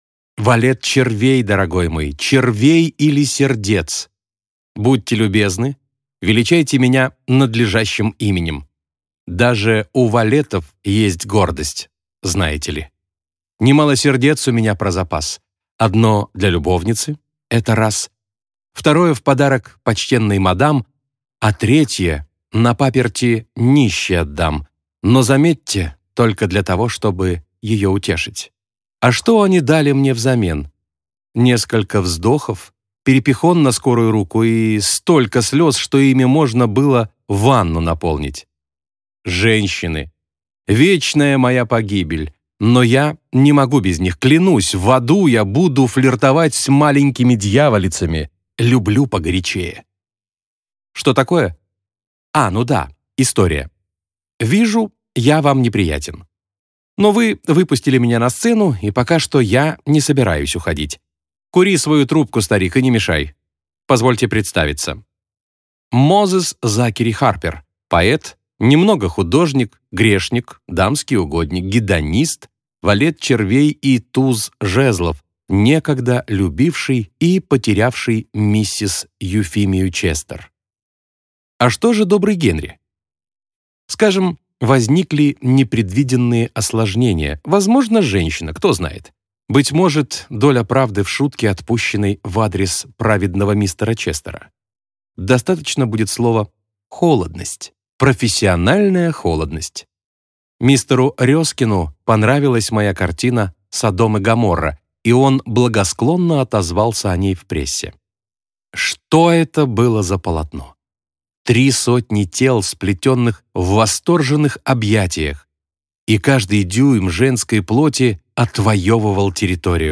Аудиокнига Темный ангел - купить, скачать и слушать онлайн | КнигоПоиск